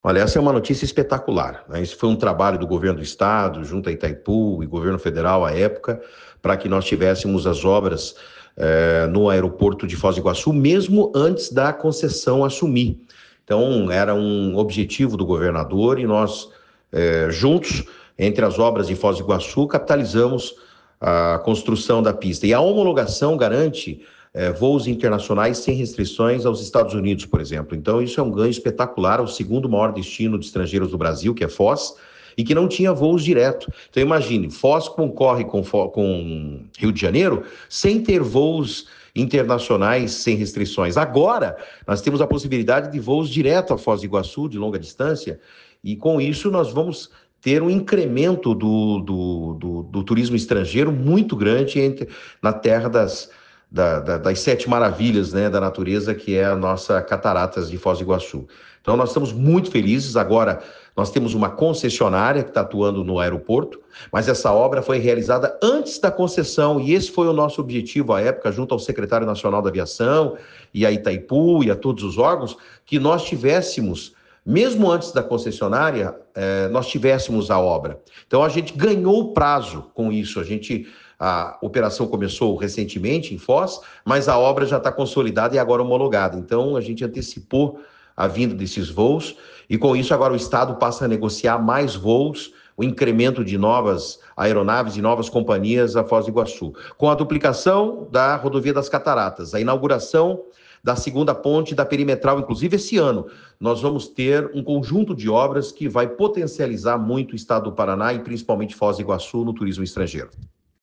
Sonora do secretário de Infraestrutura e Logística, Sandro Alex, sobre a nova pista no Aeroporto de Foz do Iguaçu